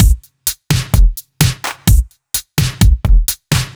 Index of /musicradar/french-house-chillout-samples/128bpm/Beats
FHC_BeatA_128-03.wav